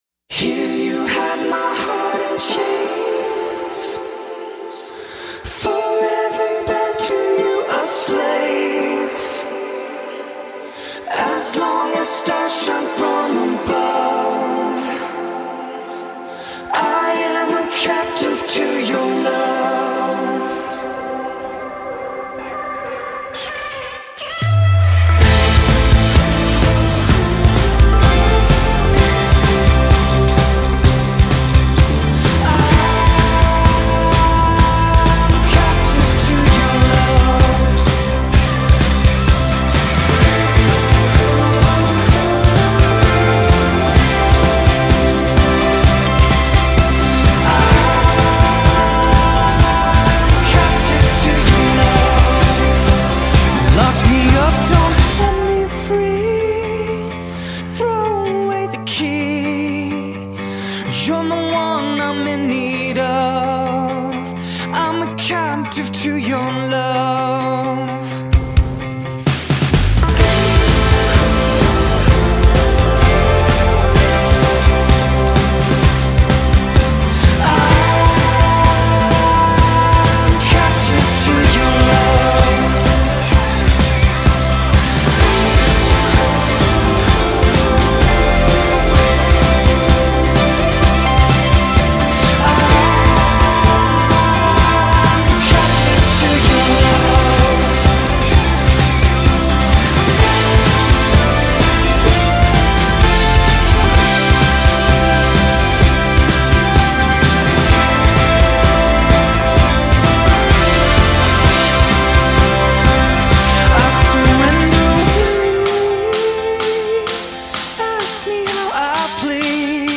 573 просмотра 366 прослушиваний 10 скачиваний BPM: 128